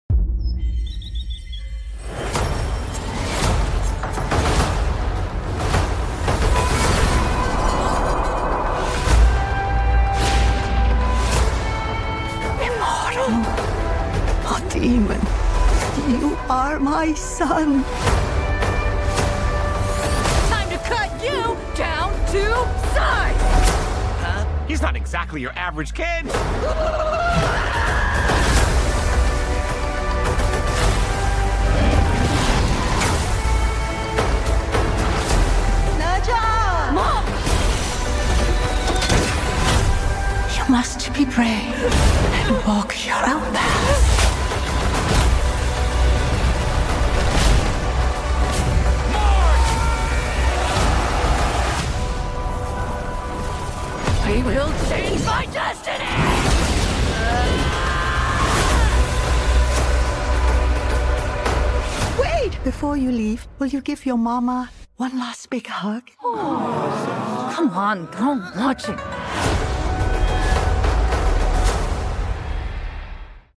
Experience the global phenomenon, NE ZHA II, with a new English-language voice cast led by Academy Award winner Michelle Yeoh.